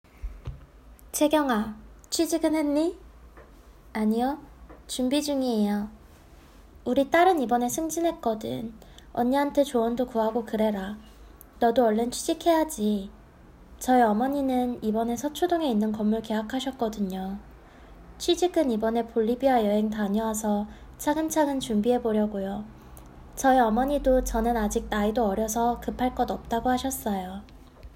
6_Conversation_B.m4a